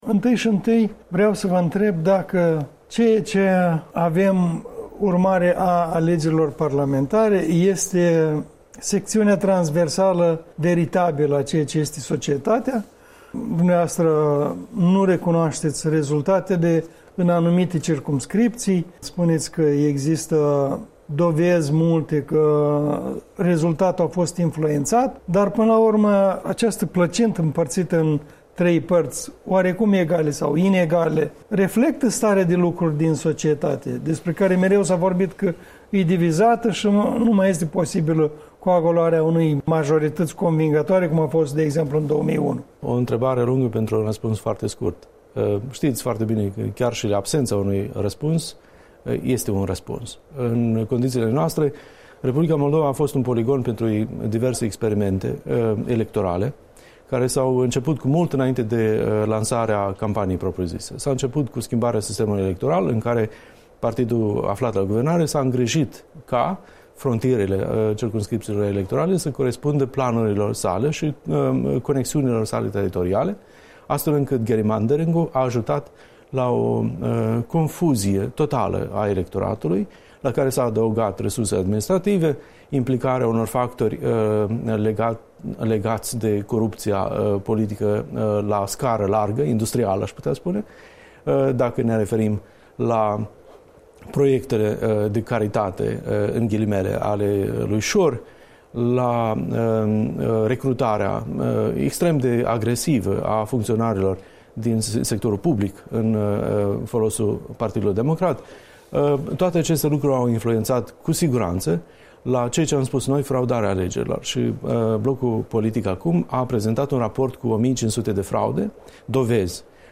Un interviu despre obiectivele Blocului ACUM după alegeri cu noul său deputat și politolog.
Un interviu cu Igor Munteanu, (deputat al Blocului ACUM)